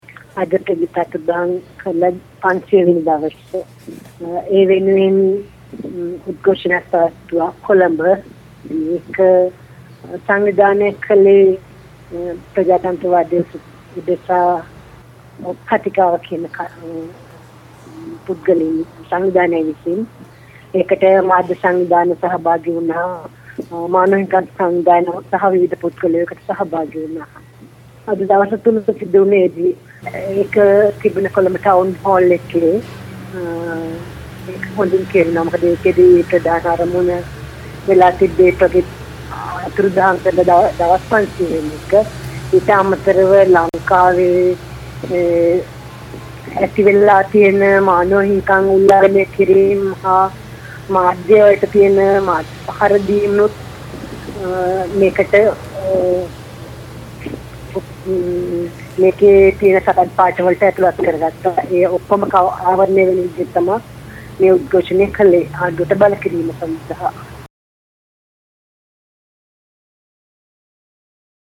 Sri Lanka - Interview de (...) 12.